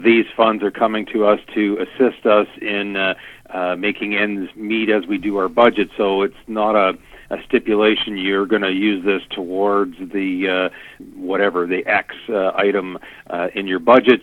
One of the main advantages of the OMPF is that the funds are relatively fluid and not necessarily earmarked for any specific project or purpose, explains Gervais: